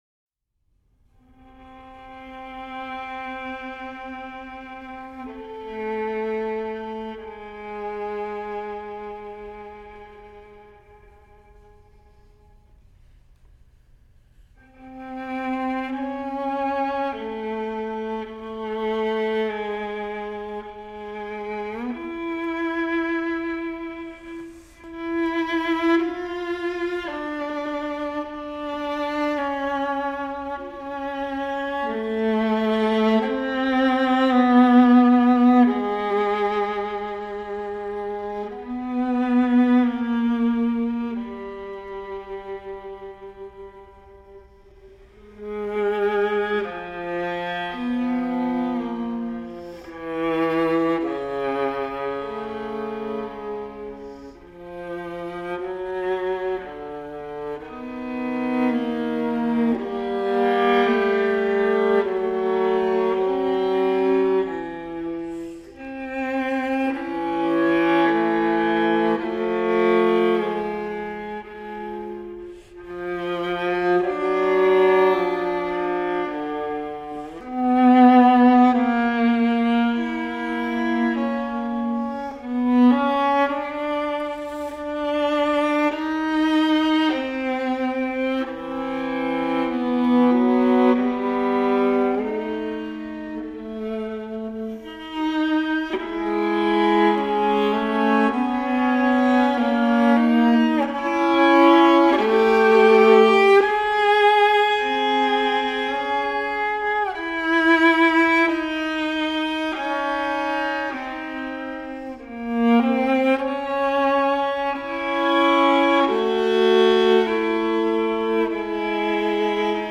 Viola
viola